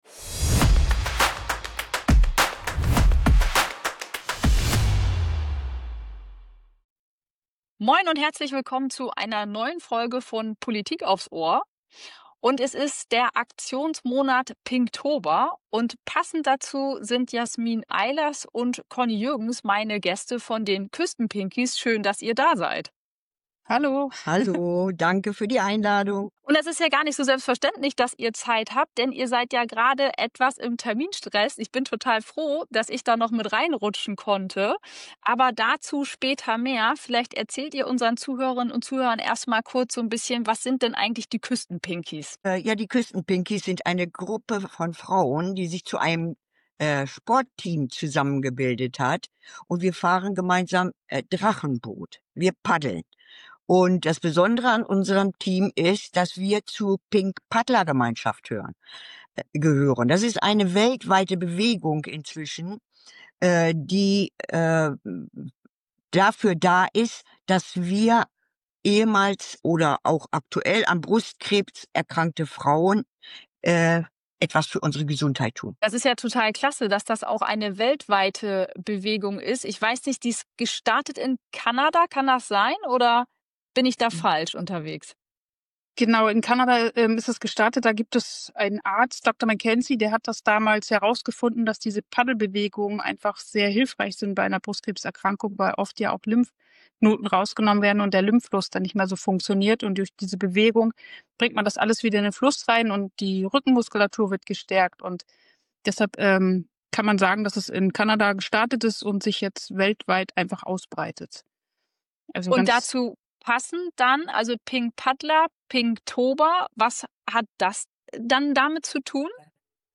Wir thematisieren die Herausforderungen im Verein, im Umgang miteinander und stellen fest, dass es noch mehr Aufmerksamkeit und auch finanzielle Unterstützung braucht. Ein mutiges Gespräch über Empowerment, Prävention und die Kraft der Solidarität.